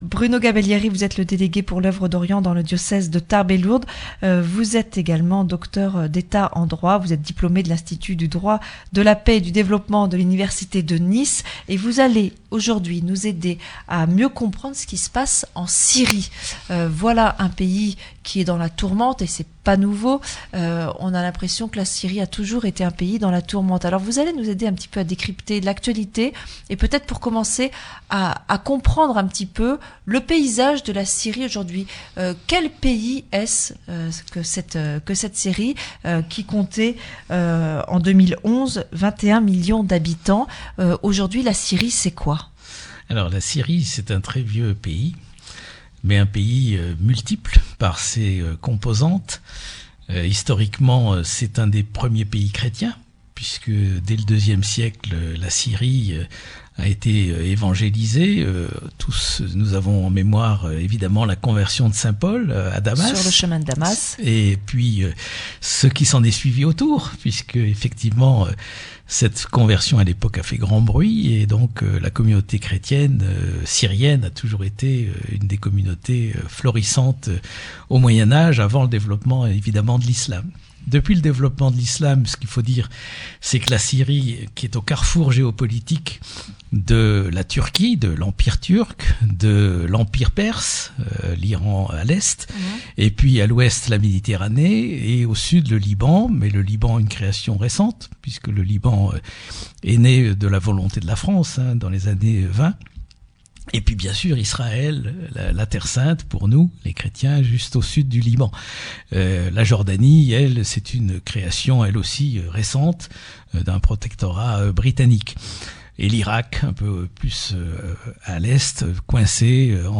Accueil \ Emissions \ Information \ Locale \ Interview et reportage \ Que comprendre de la situation en Syrie ?